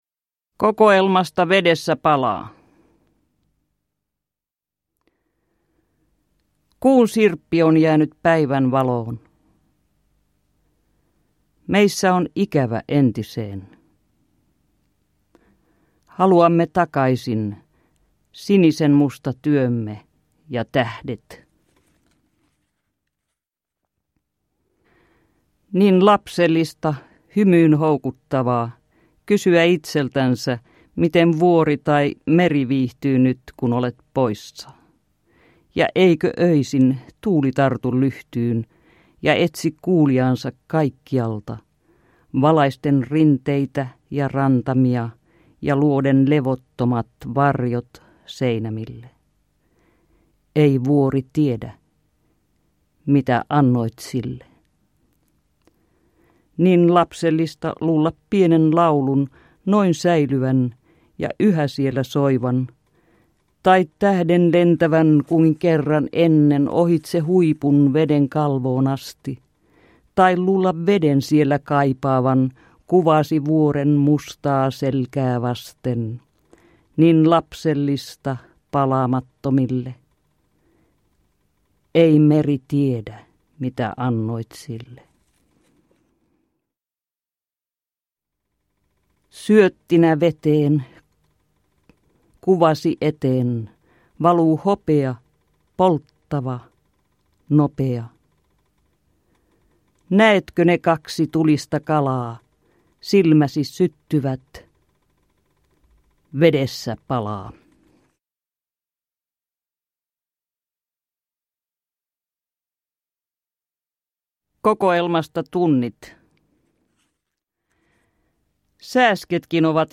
Produkttyp: Digitala böcker
Kukin äänikirja oli kokoelma kirjailijan siihenastisista teksteistä: tekijän valikoimia runoja ja otteita proosasta omalla äänellä luettuina, omilla painotuksilla ja tauoilla.
Elävänä Bulevardilla – Mirkka Rekola –äänikirjassa kirjailija lukee otteita teoksistaan Vedessä palaa, Tunnit, Syksy muuttaa linnut, Ilo ja epäsymmetria, Anna päivän olla kaikki, Muistikirja, Minä rakastan sinua, minä sanon sen kaikille, Tuulen viime vuosi, Kohtaamispaikka vuosi, Maailmat lumen vesitöissä, Kuutamourakka, Puun syleilemällä, Silmänkantama, Tuoreessa muistissa kevät, Kuka lukee kanssasi sekä Maskuja.
Uppläsare: Mirkka Rekola